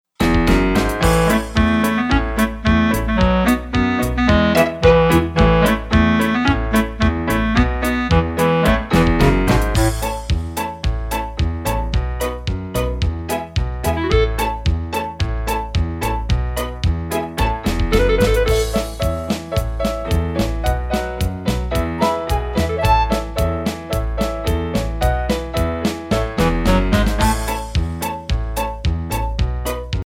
SOCIAL EMOTIONAL INSTRUMENTAL TRACKS